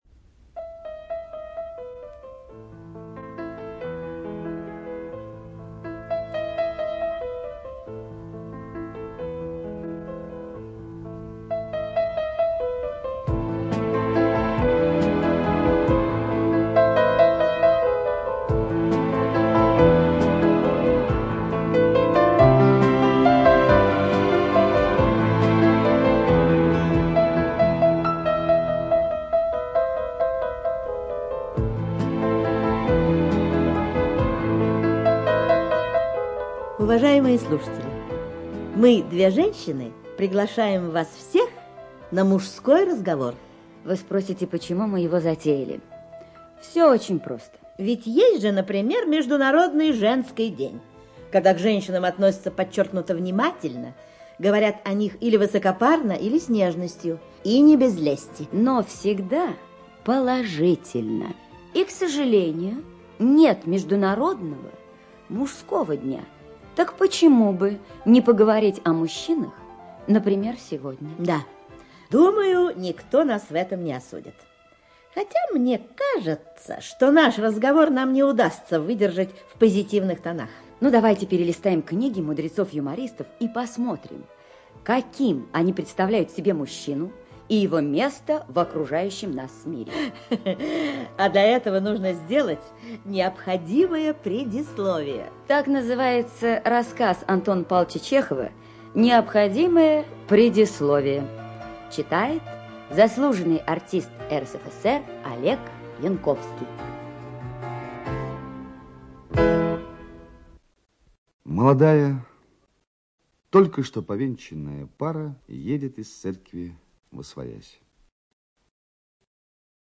ЧитаютЯковлев Ю., Дробышева Н., Зеленая Р., Янковский О., Целиковская Л.